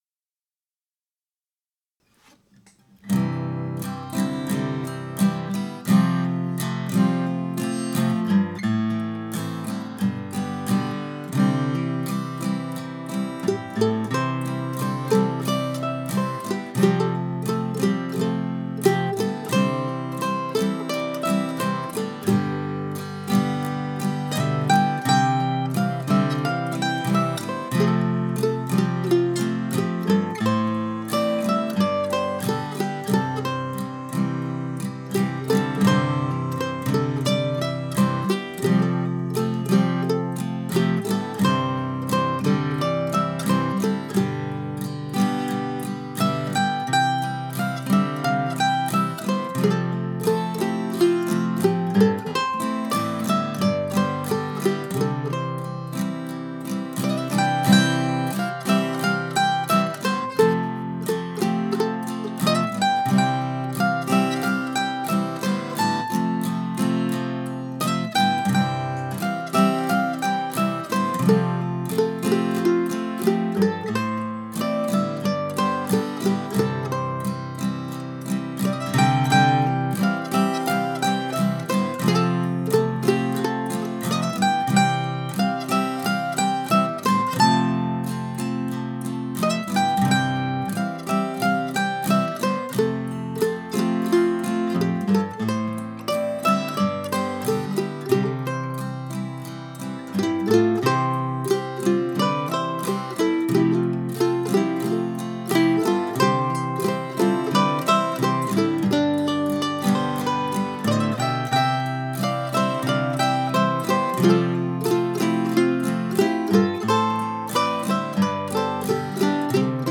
Back on the morning of August 5 I was sitting here at my desk doodling on the mandolin.
If you are a reader of Wendell Berry's poetry, novels and essays I suspect that this connection alone will help you enjoy this very plain and simple bit of music.